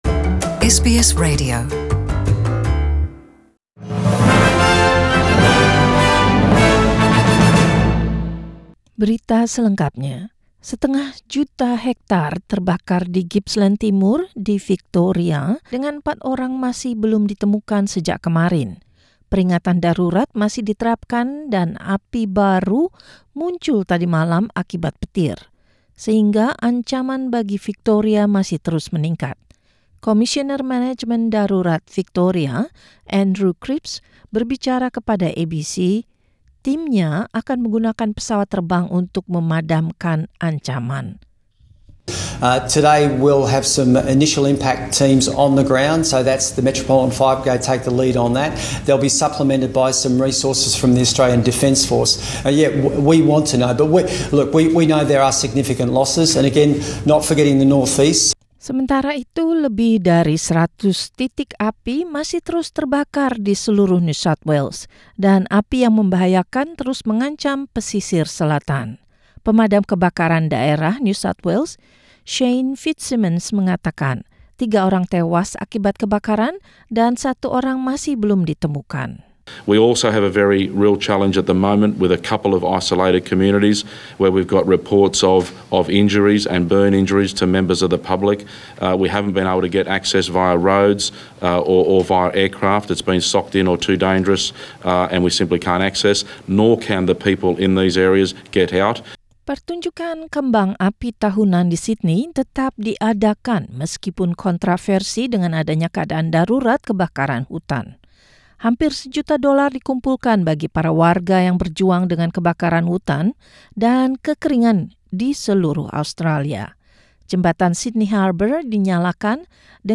Warta Berita Radio SBS dalam Bahasa Indonesia, 1 Januari 2020 Source: SBS